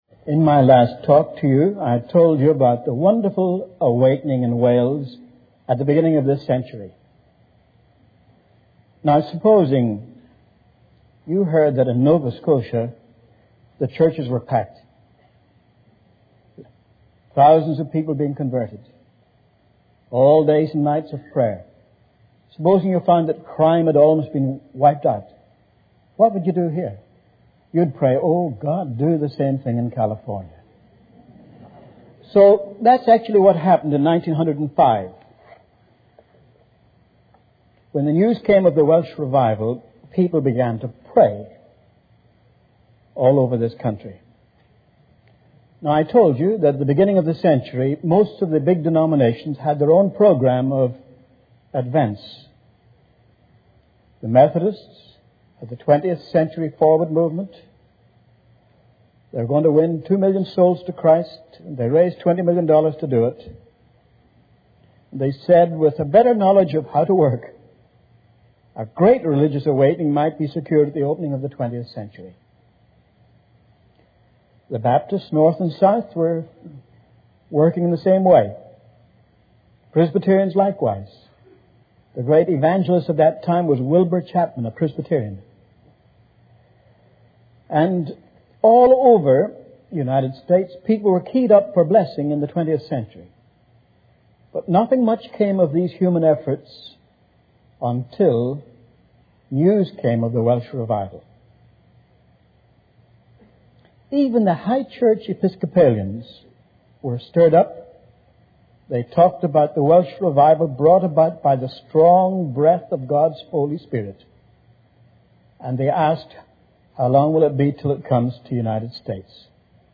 This sermon recounts the powerful revival that swept through the United States in 1905, sparked by the Welsh revival. It emphasizes the impact of prayer, the outpouring of the Holy Spirit, and the transformation of individuals, churches, and communities. The sermon highlights the unstructured nature of the revival, the emphasis on repentance and new life, and the societal reforms and civic righteousness that followed in various cities.